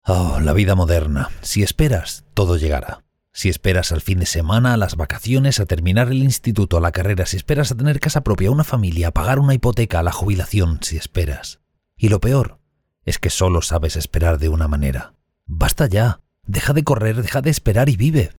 Spanish Castilian male Voice Over (Baritone) Warm and deep voice talent.
Sprechprobe: Sonstiges (Muttersprache):
Sweet voice, warm, deep, energetic ... very adaptable voice.
CHARACTER IMPERSONATION.mp3